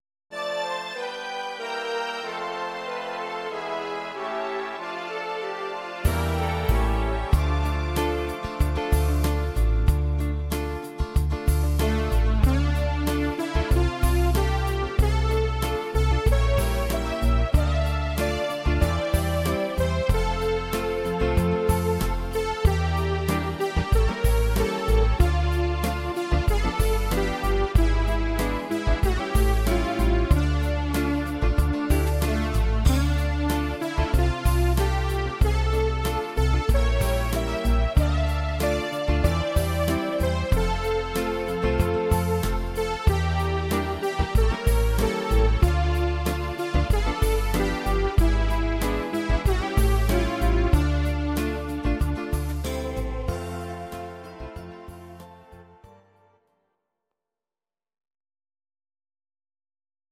Audio Recordings based on Midi-files
Instrumental